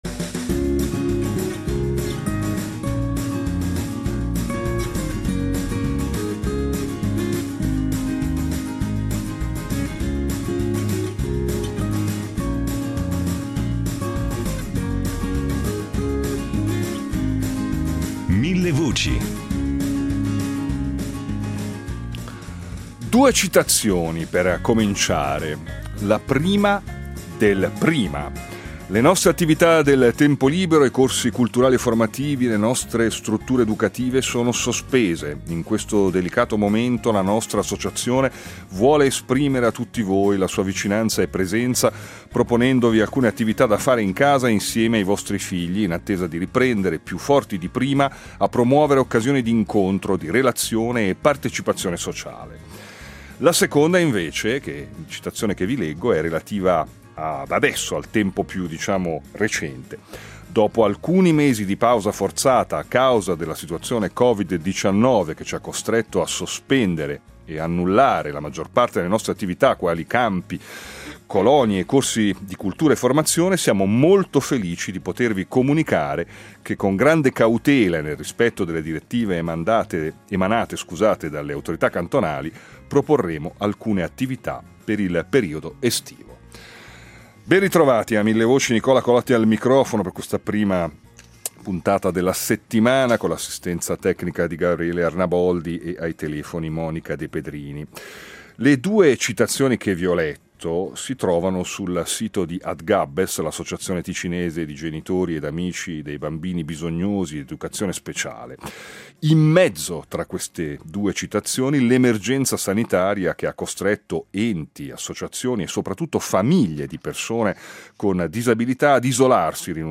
Genitori di persone con disabilità